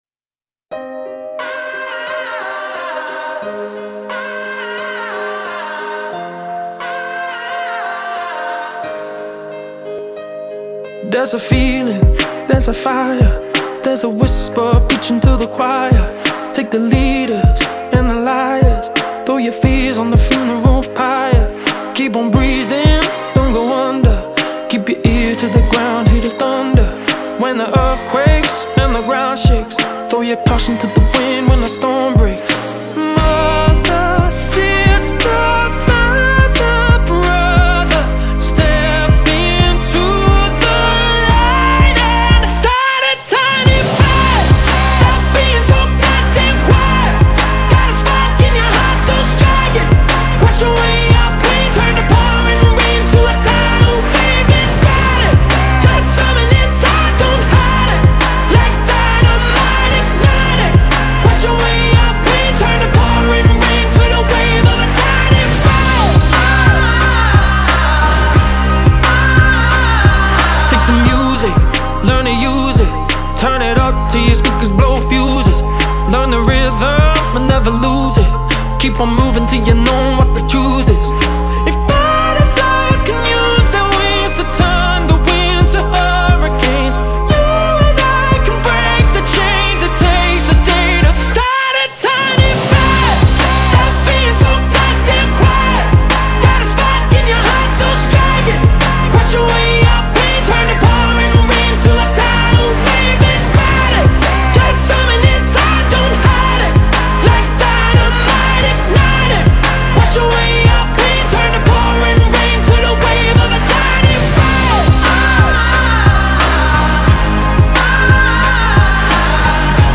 یک خواننده و ترانه نویس بریتانیایی